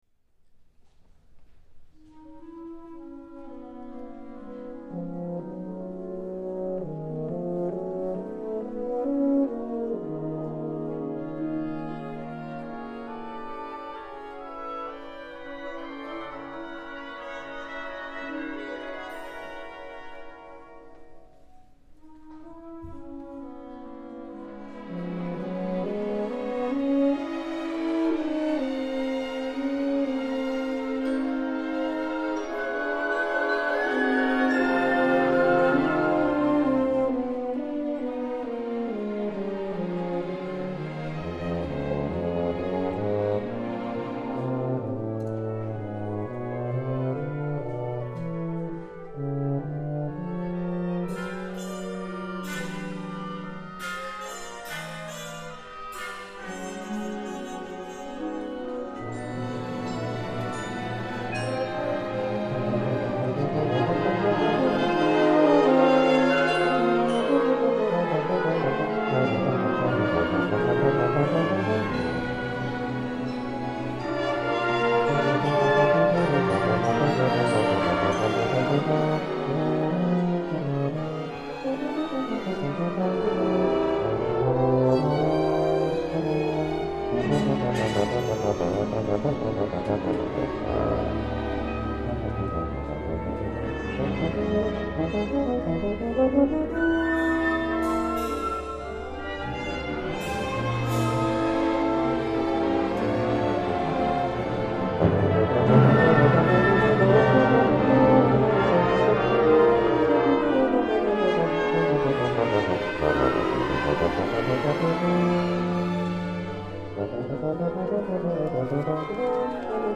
Tuba and Youth Orchestra